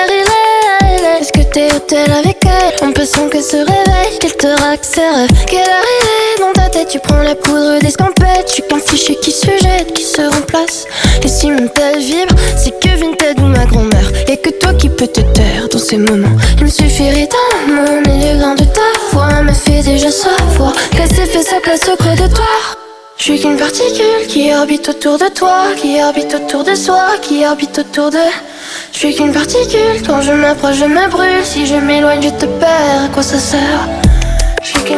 French Pop
2025-09-10 Жанр: Поп музыка Длительность